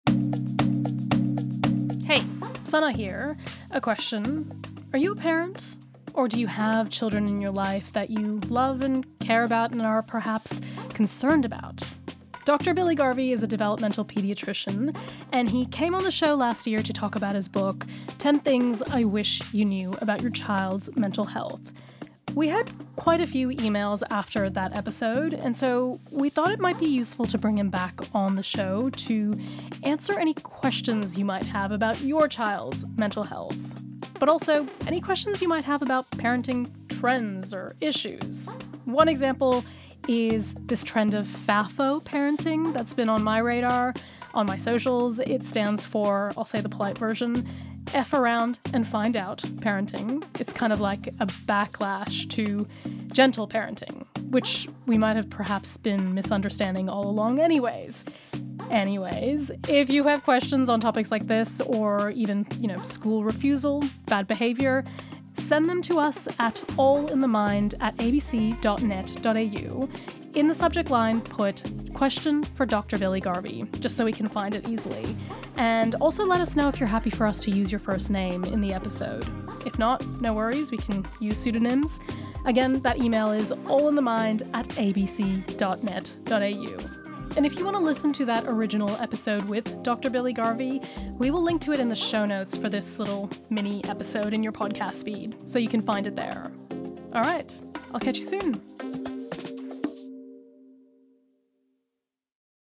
Download - Dissecting the brain - live at the World Science Festival Brisbane | Podbean